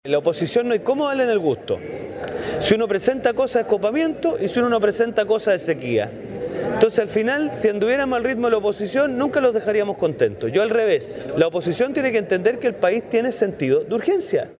Por ejemplo, el diputado Diego Schalper (RN) señaló que la oposición no valora el cambio de forma y ritmo que comenzamos a ver desde el 11 de marzo.